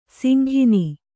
♪ singhini